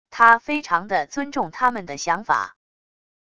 他非常的尊重他们的想法wav音频生成系统WAV Audio Player